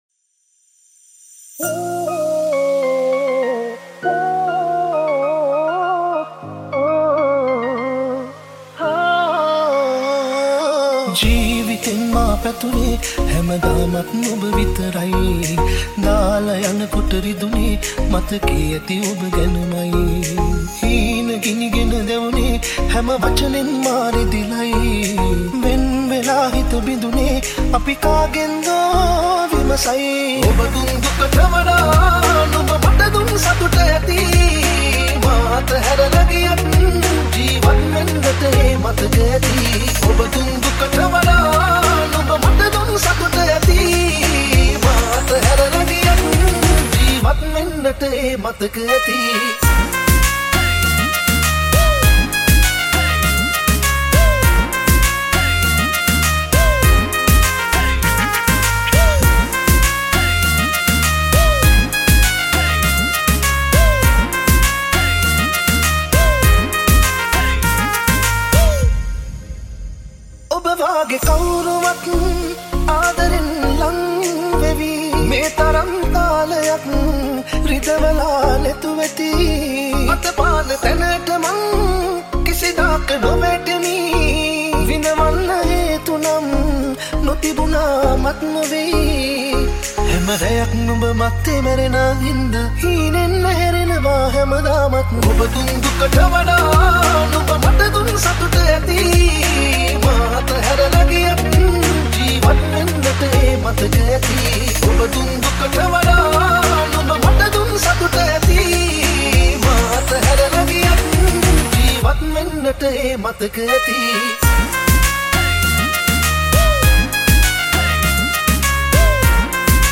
Genre - Pop